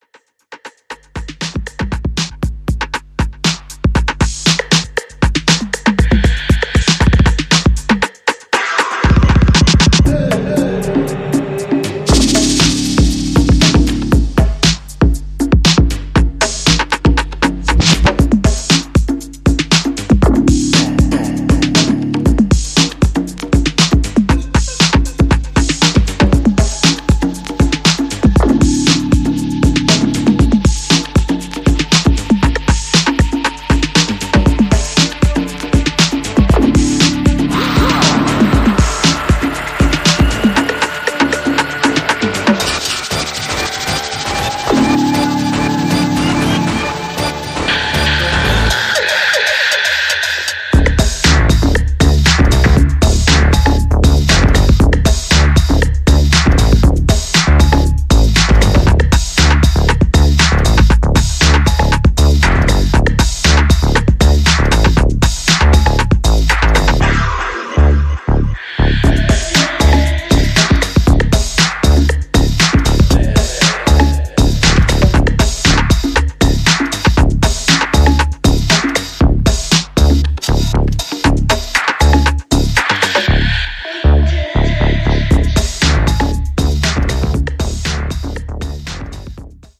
本作では、クラシカルなエレクトロや初期UKハウス、アシッド/プロト・ハウス等をオマージュした楽曲を展開。